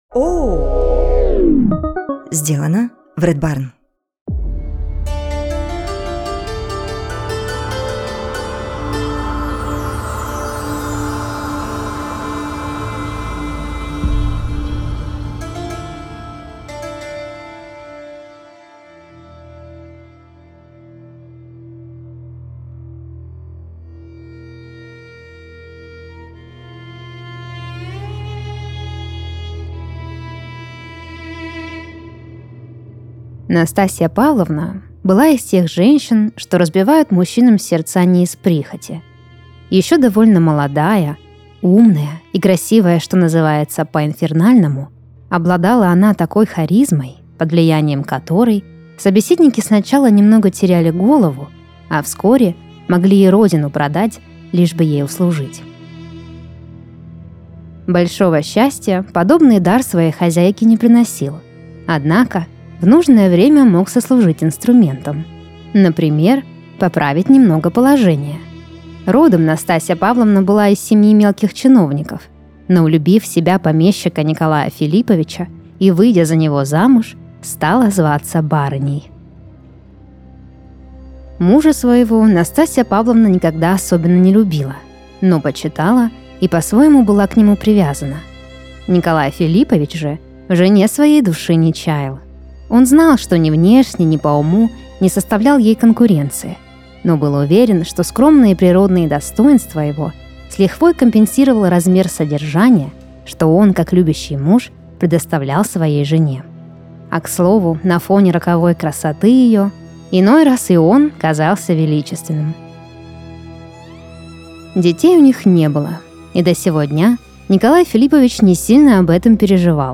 Каждый выпуск слушатели присылают нам свои сны, а мы переделываем их в художественный рассказ и зачитываем под расслабляющую музыку.